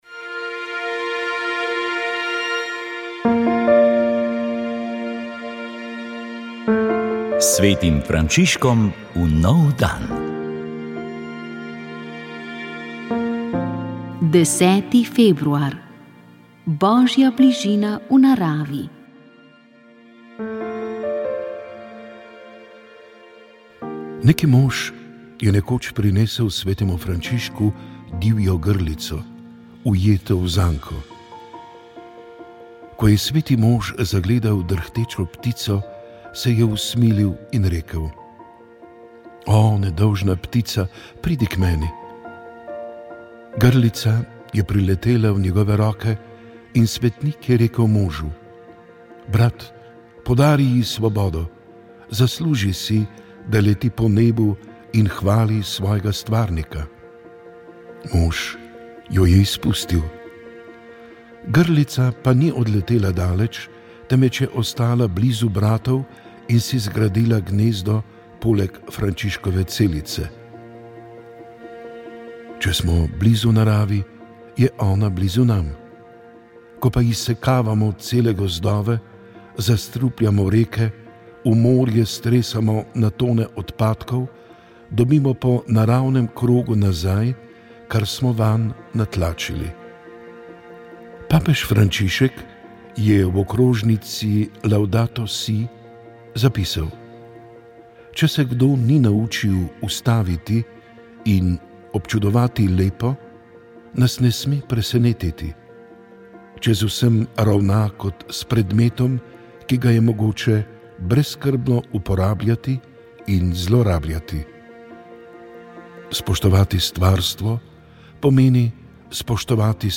Sveta maša
Sv. maša iz bazilike Marije Pomagaj na Brezjah 19. 5.